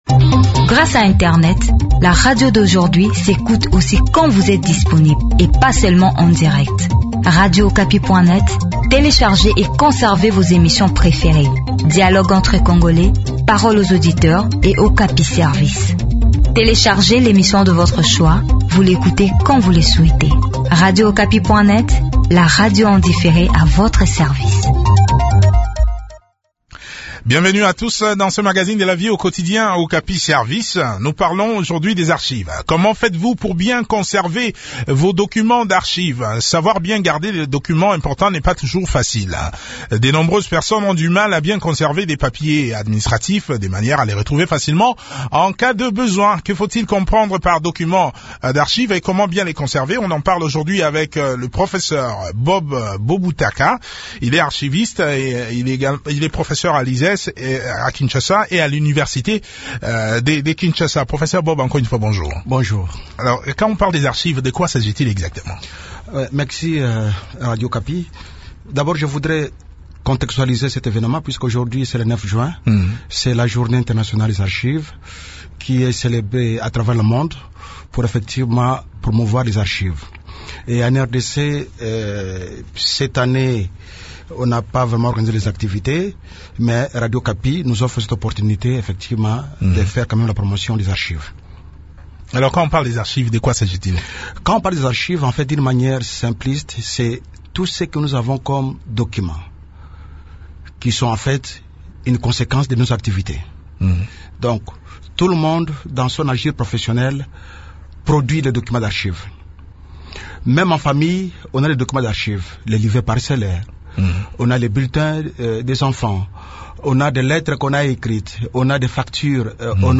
Cet entretien est réalisé à l’occasion de la Journée internationale des archives célébrée le 9 juin de chaque année.